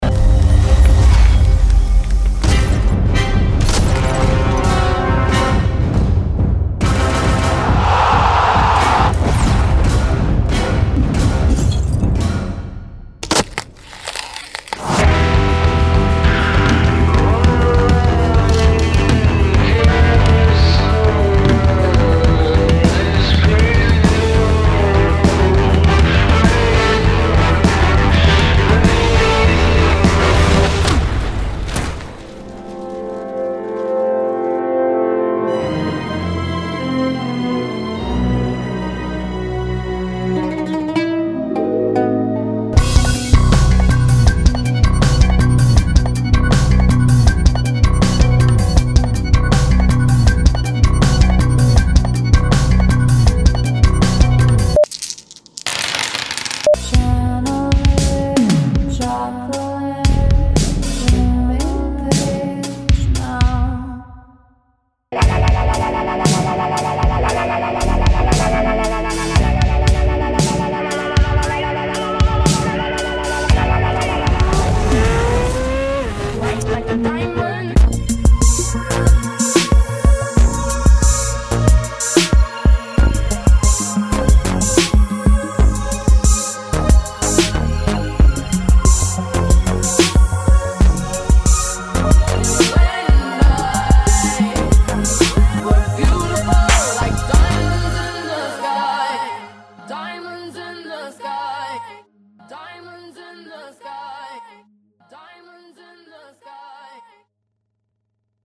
This piece was entirely composed using virtual instruments, but its essence is deeply rooted in authenticity. Every note and sound comes from a meticulously crafted database of recordings of real instruments.
The interplay of tribal rhythms and orchestral melodies required careful attention to dynamics, tempo, and texture to create a seamless integration of the two styles.